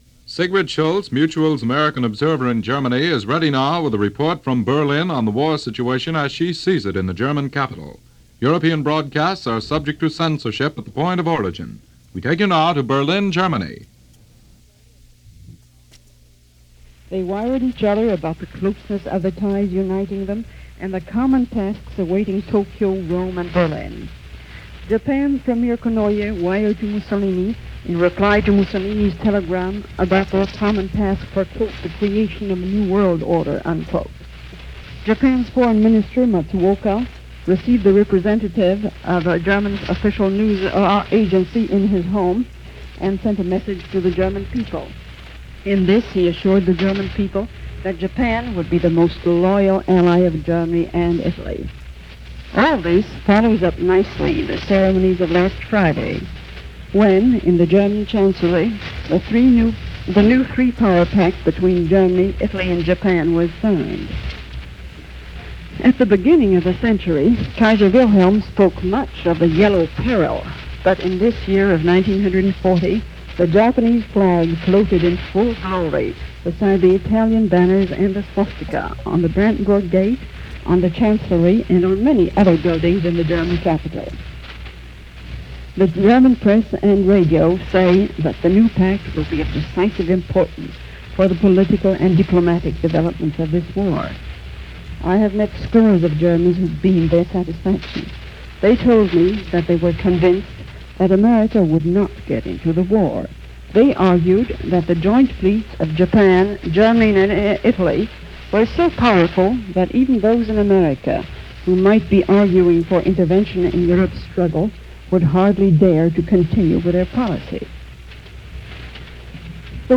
September 29, 1940 – News for this day, direct from Berlin and reported by Sigrid Schultz, correspondent for Mutual as well as The Chicago Tribune. The story was the signing of the Axis Tripartite between Germany, Italy and Japan – solidifying the Axis powers into one powerful force.
But this is how it was sounding from Berlin to the rest of the world via Mutual Broadcasting on September 29, 1940.